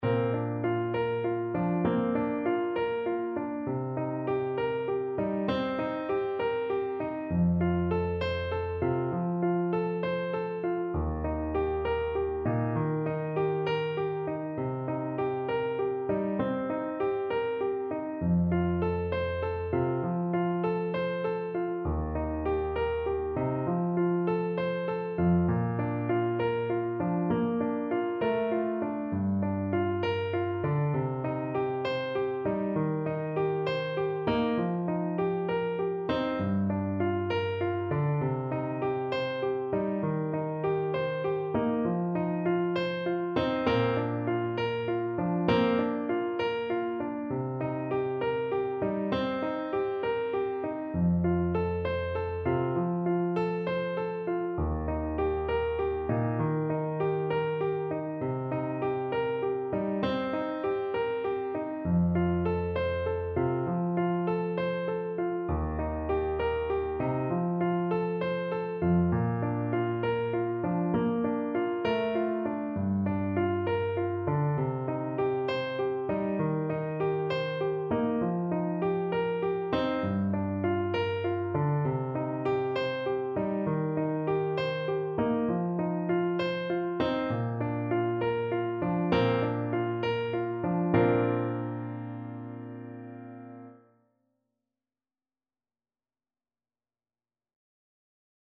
6/8 (View more 6/8 Music)
Bb3-D6